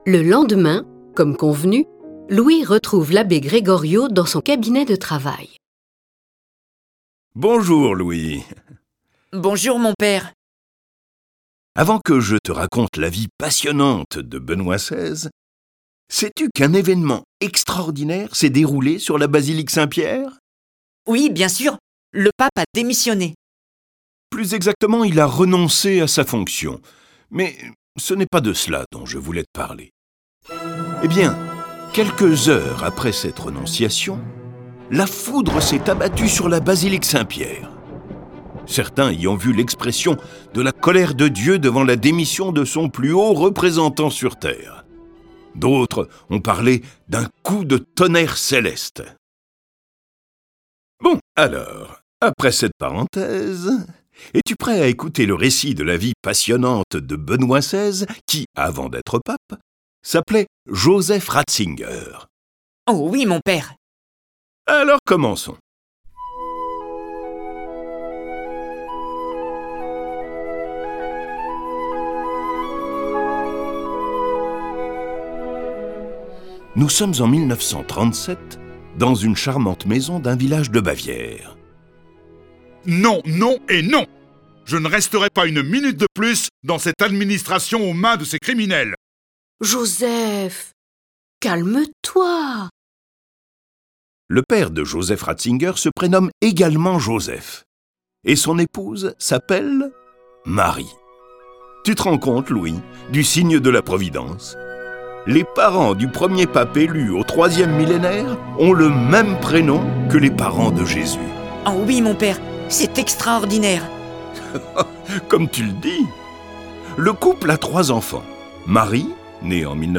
Diffusion distribution ebook et livre audio - Catalogue livres numériques
Cette version sonore de ce récit est animée par dix voix et accompagnée de plus de trente morceaux de musique classique.